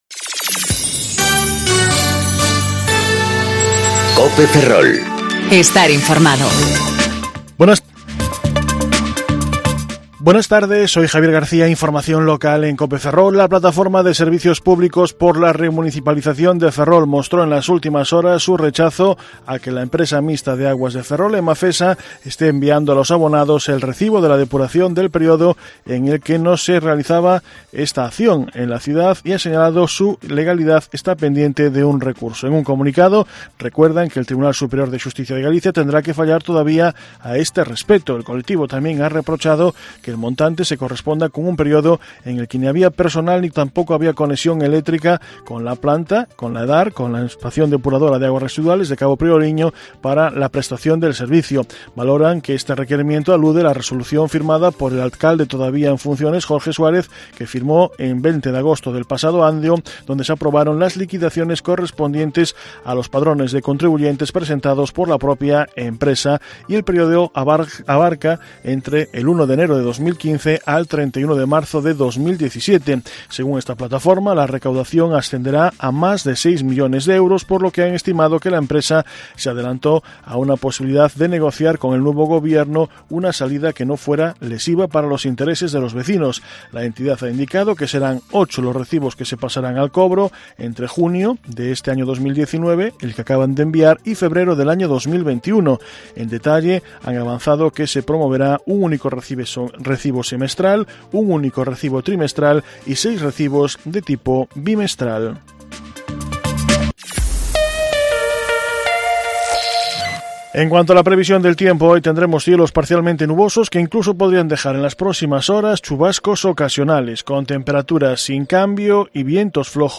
Informativo Mediodía Cope Ferrol 14/06/2019 (De 14.20 a 14.30 horas)